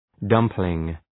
Προφορά
{‘dʌmplıŋ}